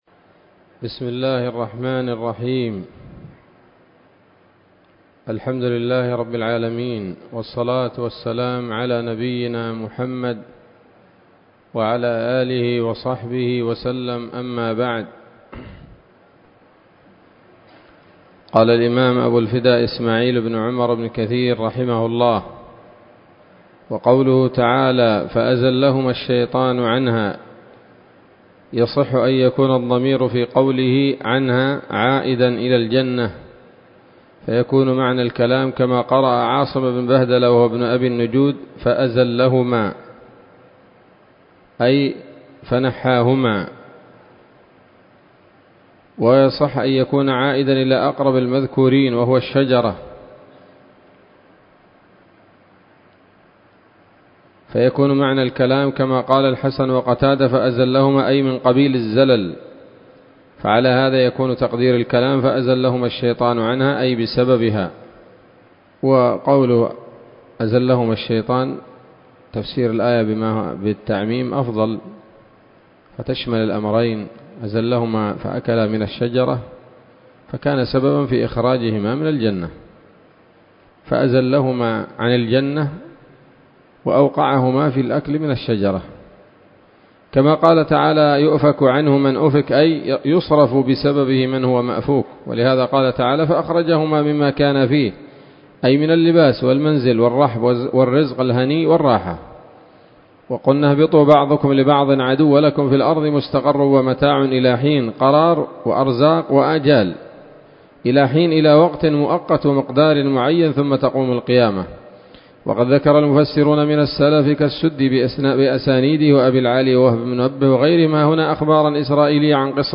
الدرس الحادي والأربعون من سورة البقرة من تفسير ابن كثير رحمه الله تعالى